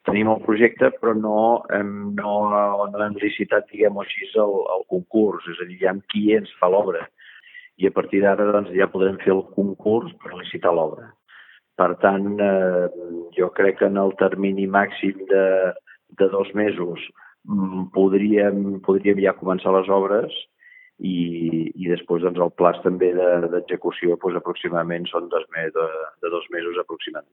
L’alcalde de Begur Joan Loureiro explica que el vial podria estar llest de cara a la temporada d’estiu.